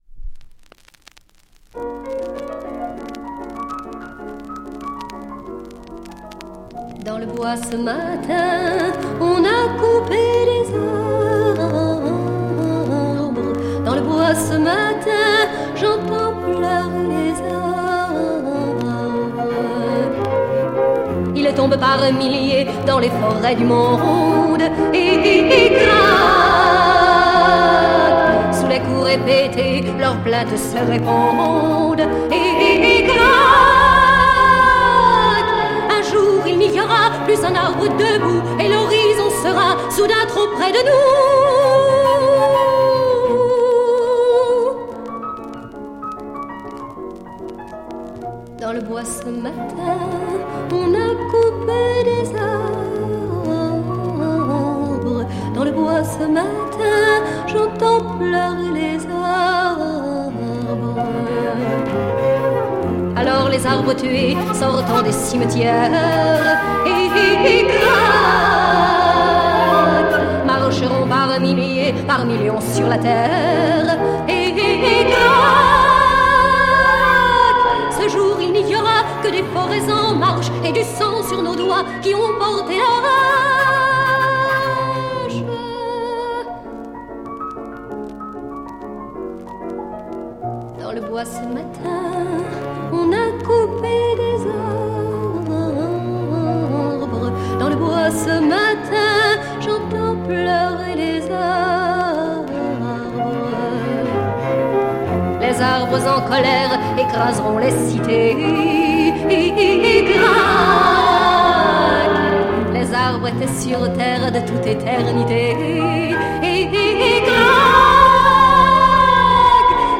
French female folk sike EP